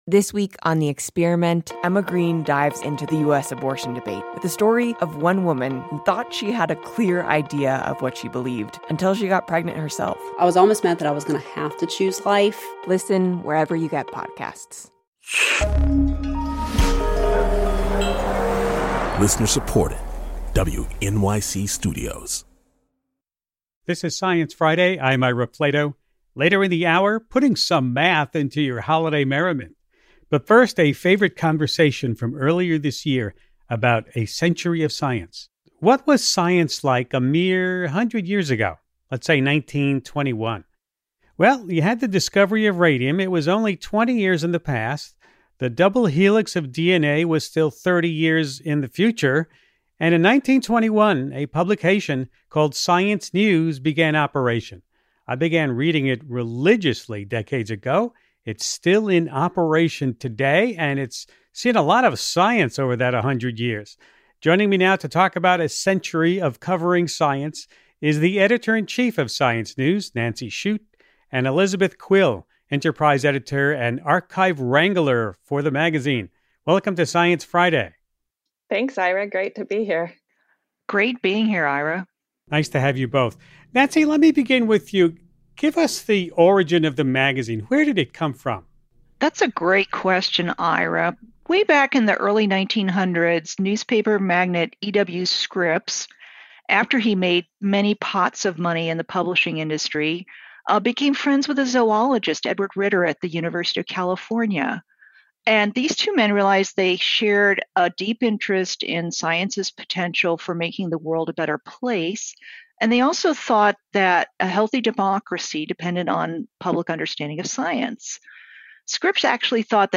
Mathematician Hannah Fry joins us to talk about how to view the holidays—and the world—from a mathematical angle.